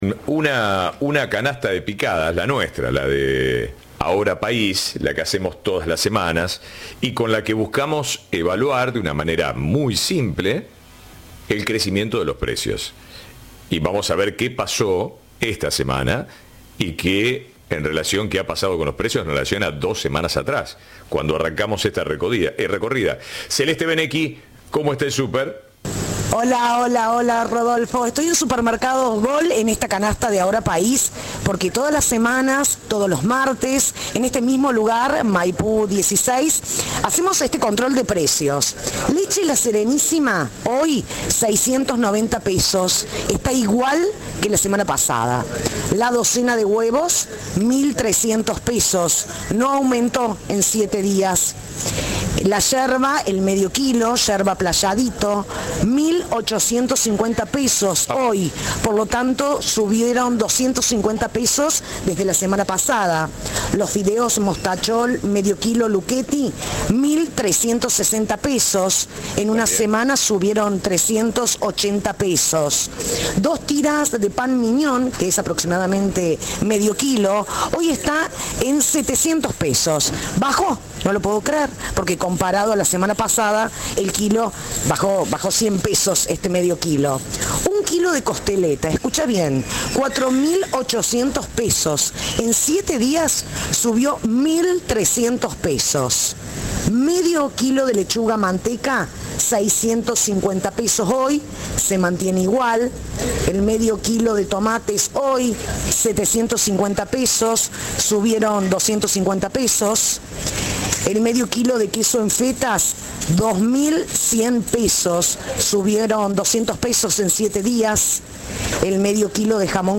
Cadena 3 hizo un nuevo relevamiento en un supermercado de la ciudad de Córdoba para destacar cuáles fueron los movimientos en los últimos siete días.